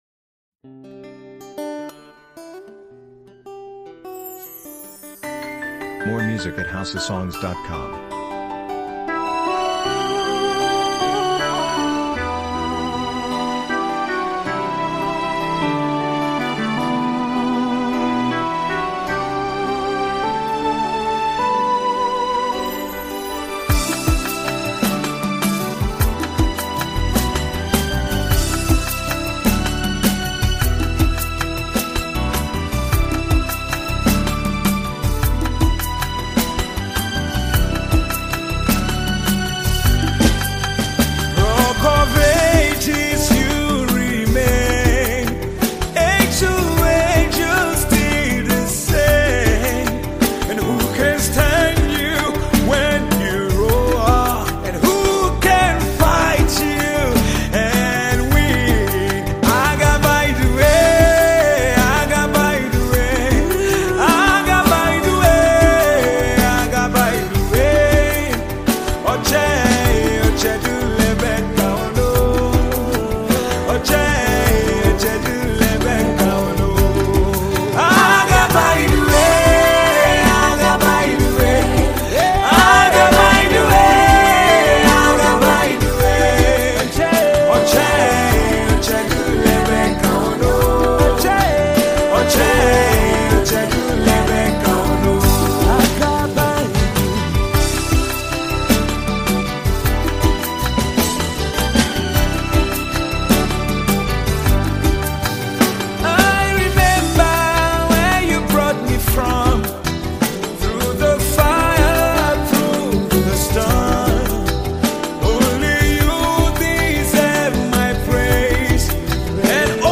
Tiv Songs